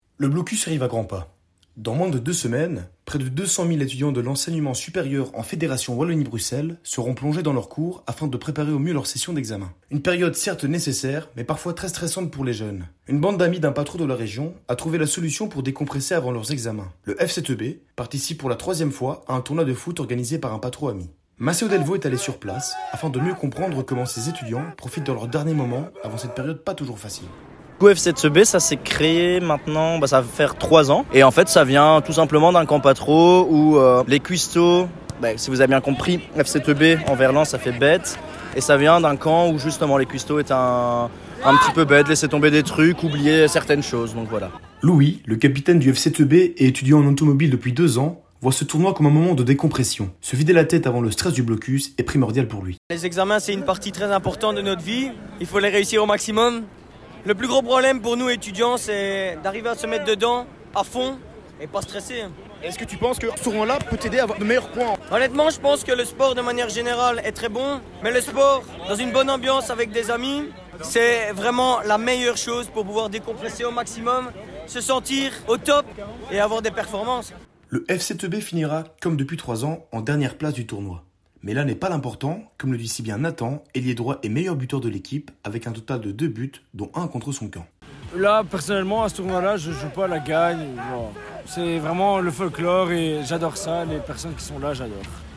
Reportage radio